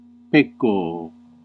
Ääntäminen
Ääntäminen : IPA : /sɪn/ US : IPA : [sɪn]